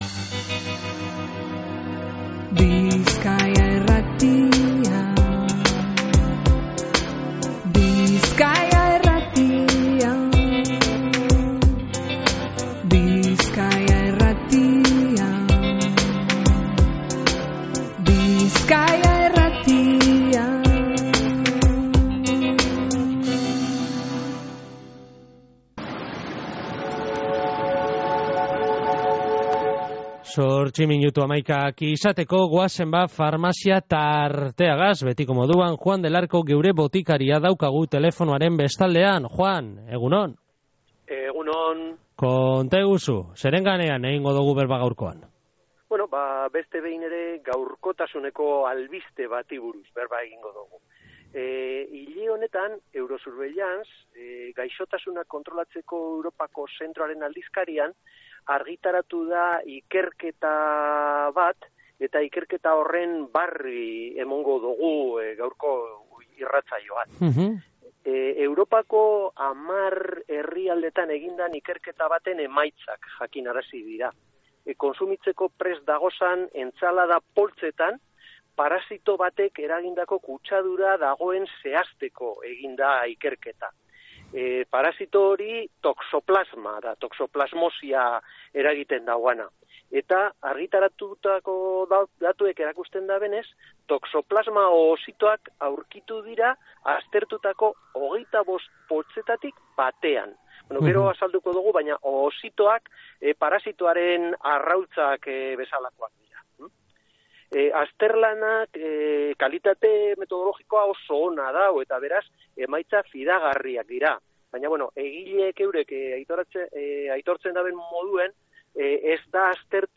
egin dogu berba asteon Aupa Bizkaia irratsaioko Farmazia tartean hilabete honetan Eurosurveillance aldizkarian argitaratu dan ikerketa baten ganean.